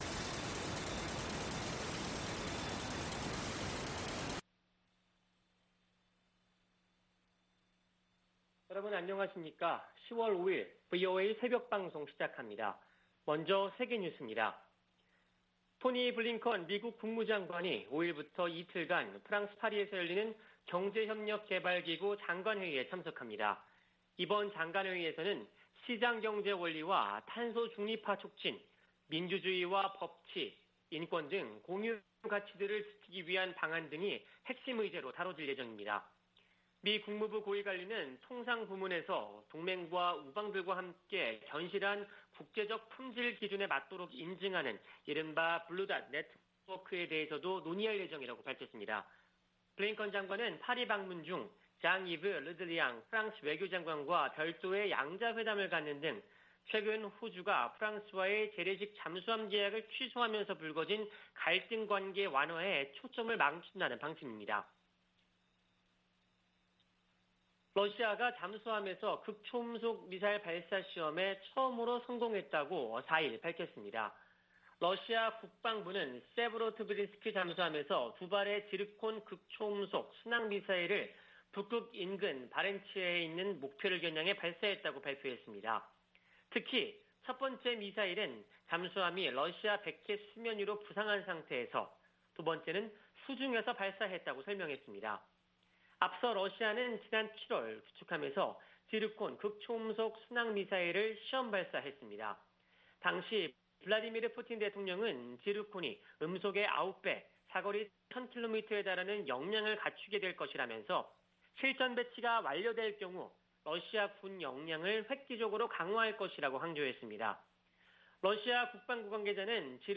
VOA 한국어 '출발 뉴스 쇼', 2021년 10월 5일 방송입니다. 북한이 일방적으로 단절했던 남북 통신연락선을 복원했습니다. 유엔 안보리 비공개 회의에서 북한의 최근 '극초음속’ 미사일 발사에 관해 논의했습니다. 미국은 북한과의 대화와 관련해 구체적인 제안을 했지만 답변을 받지 못했다고 밝혔습니다.